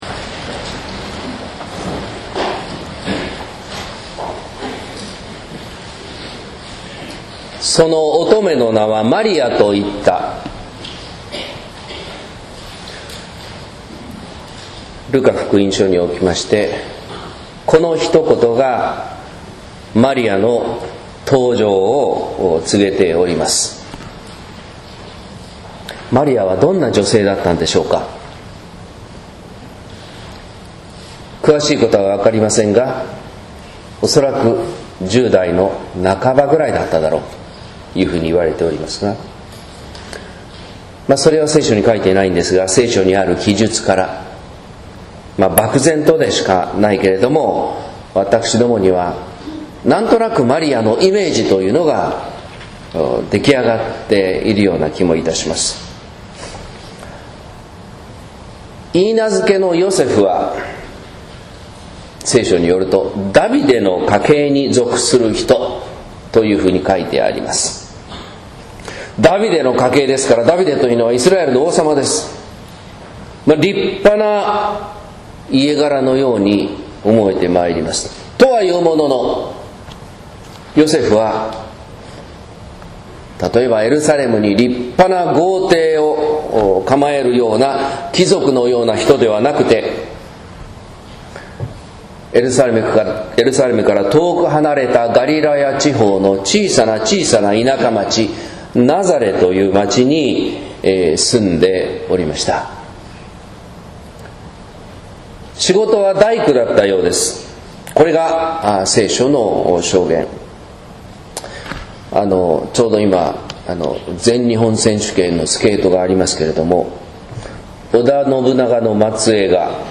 説教「幸いを歌う喜び」（音声版） | 日本福音ルーテル市ヶ谷教会